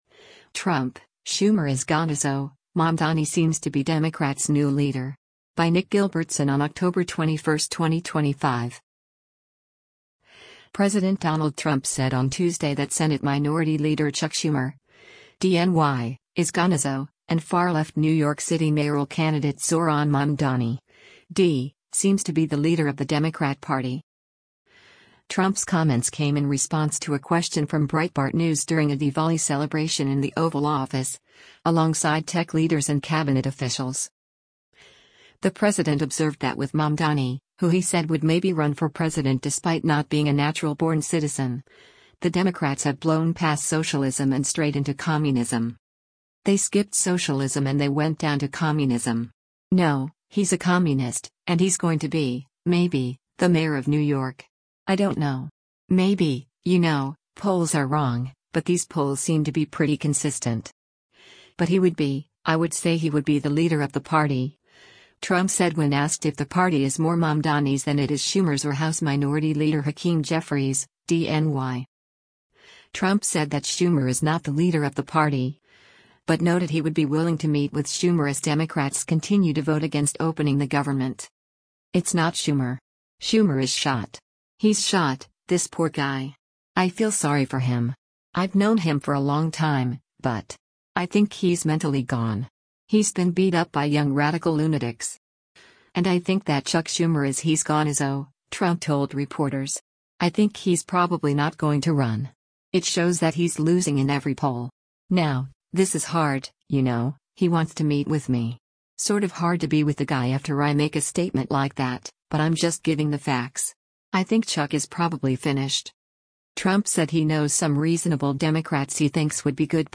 Trump’s comments came in response to a question from Breitbart News during a Diwali celebration in the Oval Office, alongside tech leaders and Cabinet officials.